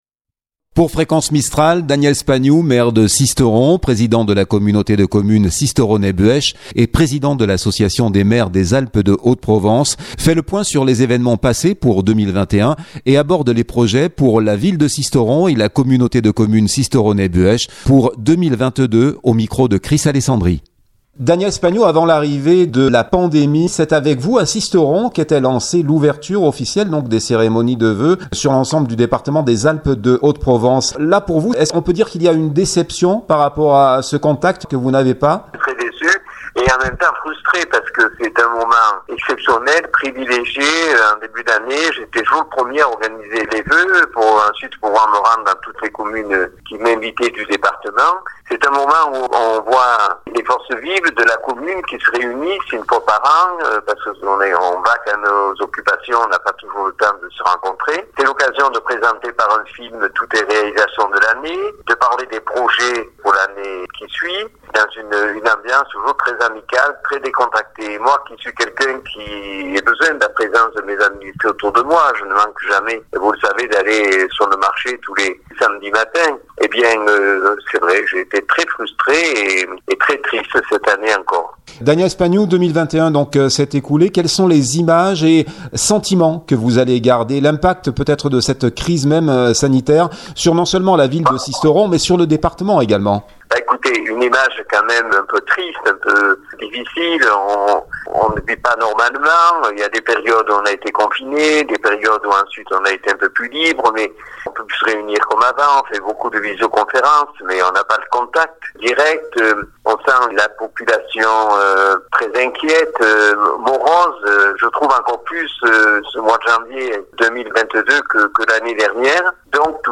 Sisteron : de 2021 à 2022, l’interview de Daniel Spagnou